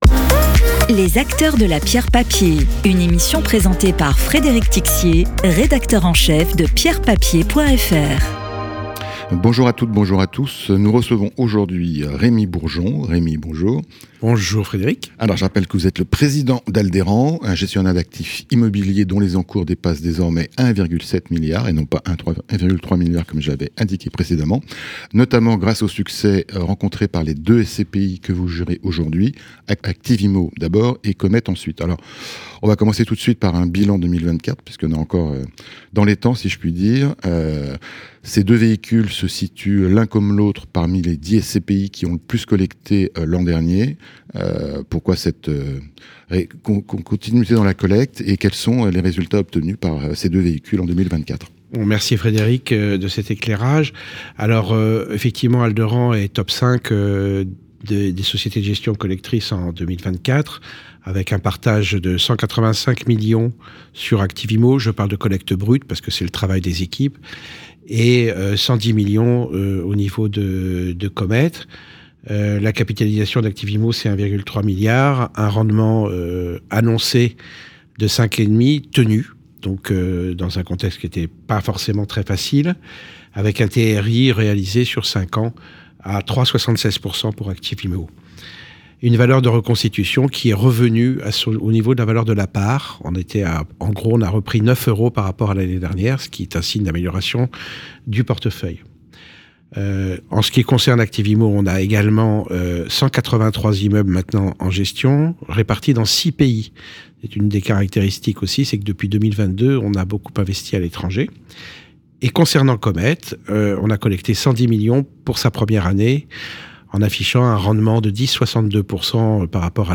Podcast d'expert